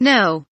know kelimesinin anlamı, resimli anlatımı ve sesli okunuşu